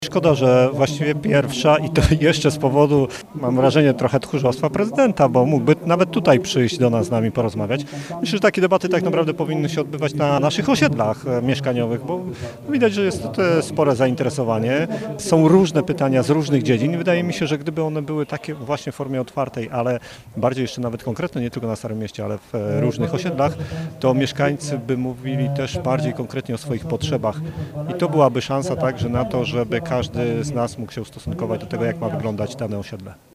Uliczna debata bez udziału prezydenta
Miała odbyć się debata w planetarium, ale na skutek odwołania jej przez sztab obecnego prezydenta, doszło do debaty na zielonogórskim deptaku.